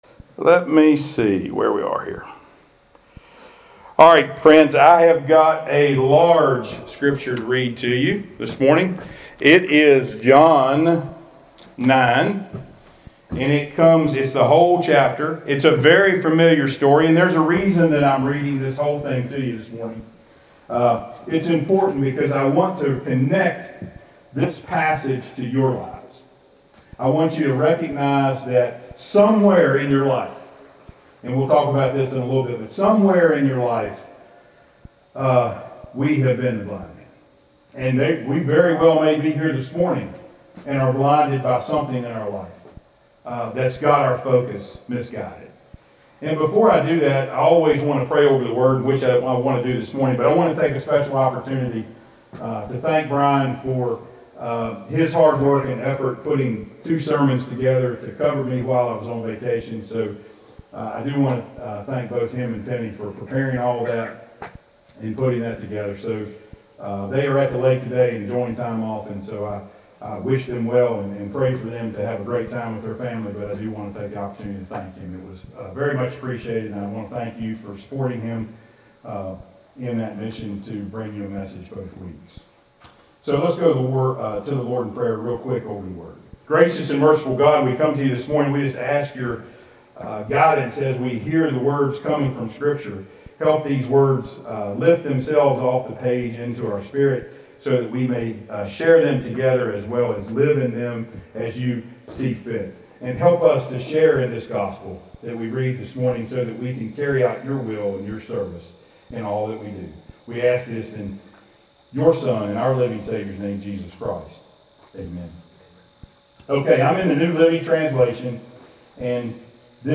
Passage: John 9:1-41 Service Type: Sunday Worship